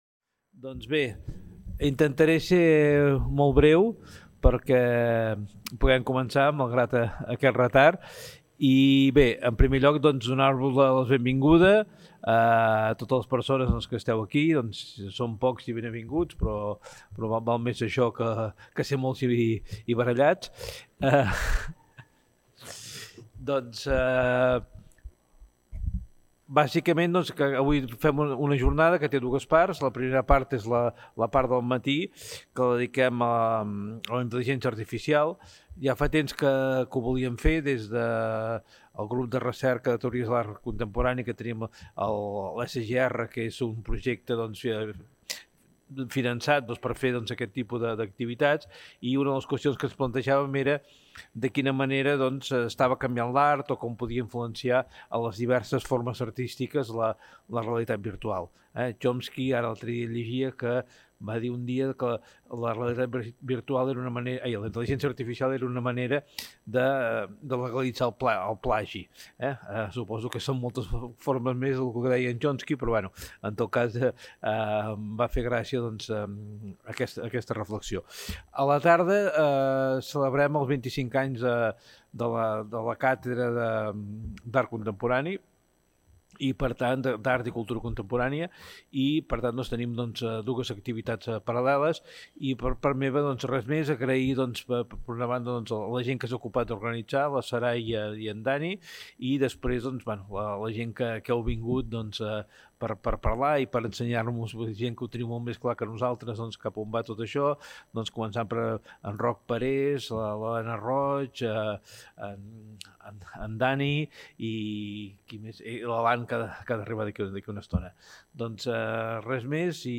Presentació de l'acte. La IA en les arts.